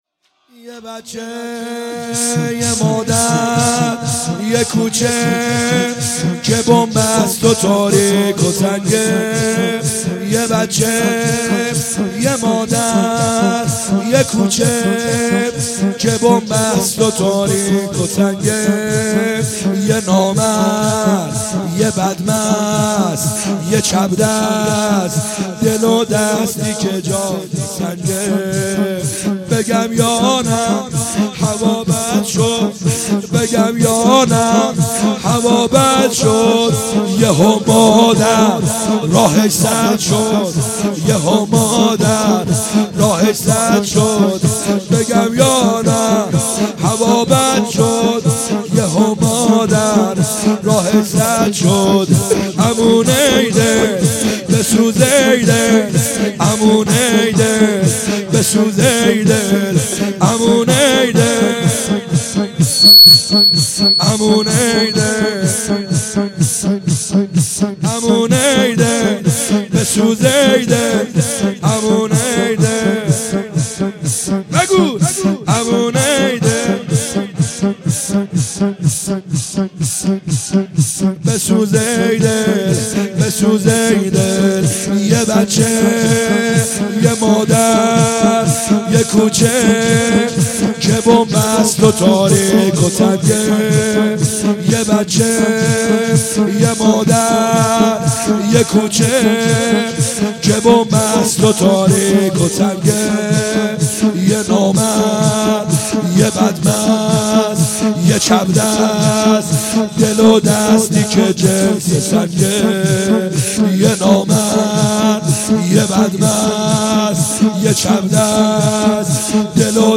خیمه گاه - بیرق معظم محبین حضرت صاحب الزمان(عج) - لطمه زنی | یه بچه یه مادر